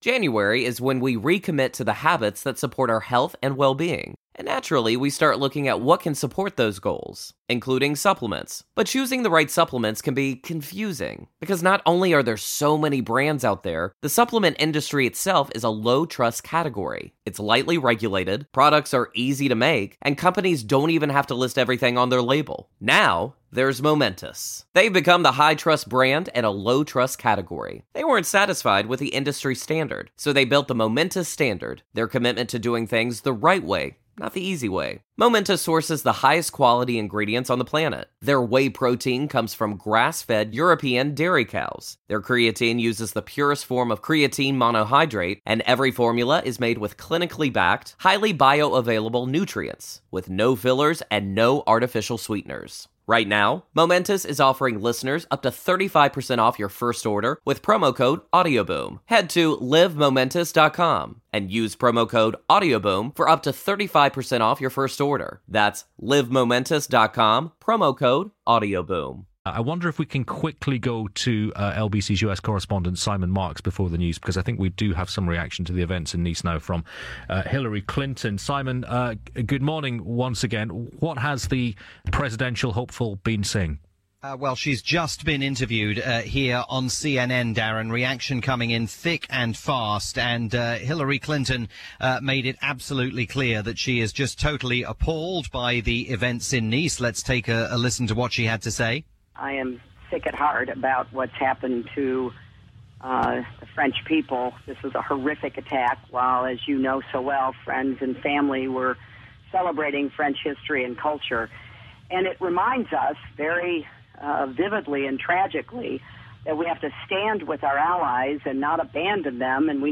kept listeners of LBC Radio in the UK up-dated from Washington